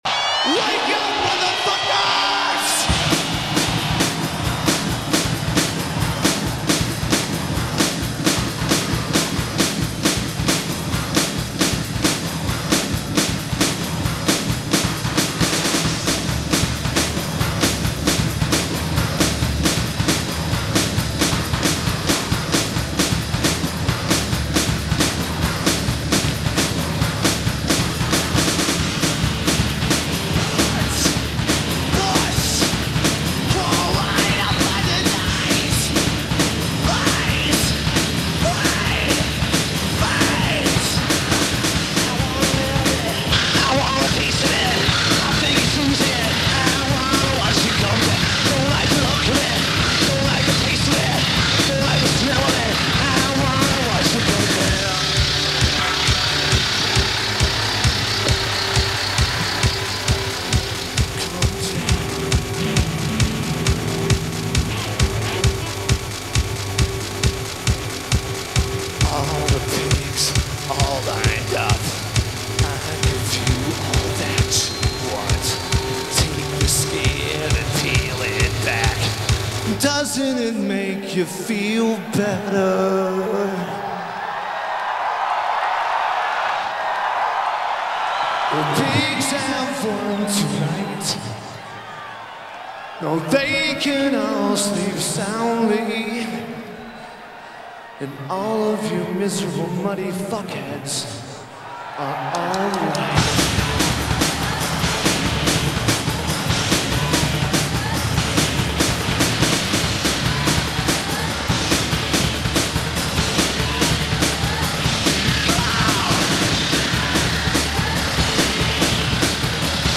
Vocals/Guitar/Keyboards
Notes: This is ripped from the pay-per-view pro-shot video.